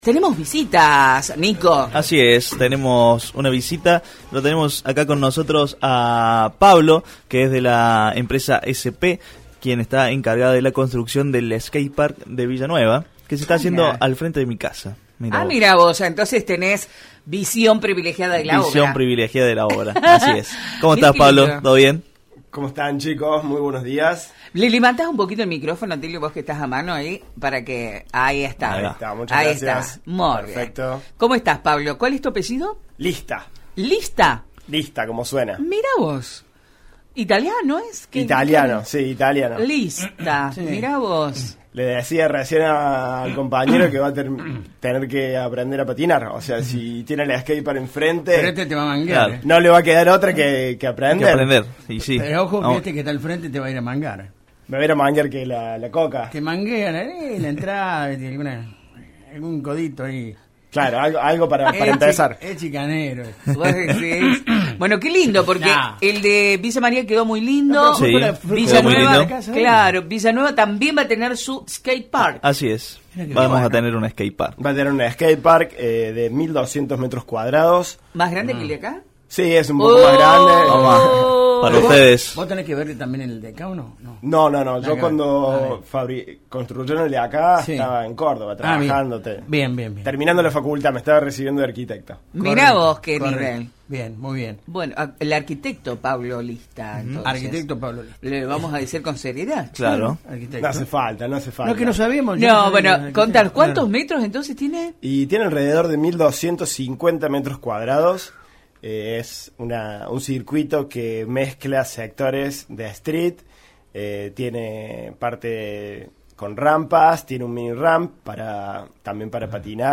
En La Mañana Informal estuvimos en diálogo